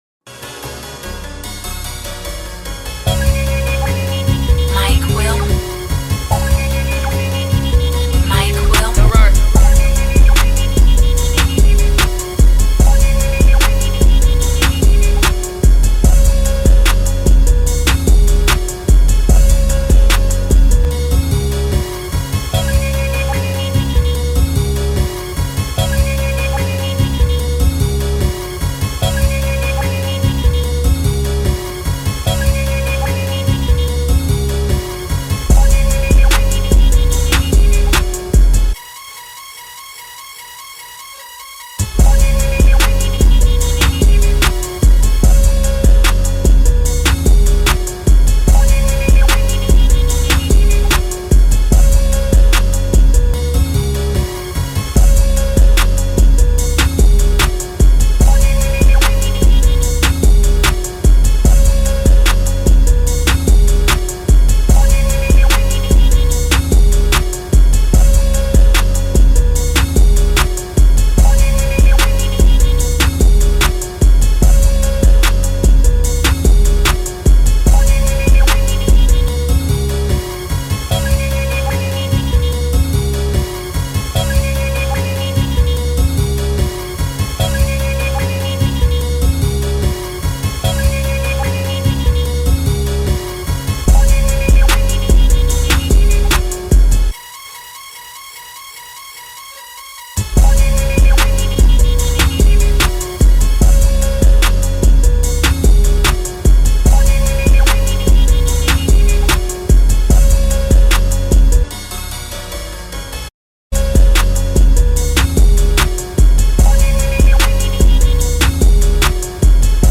instrumental version
Rap Instrumentals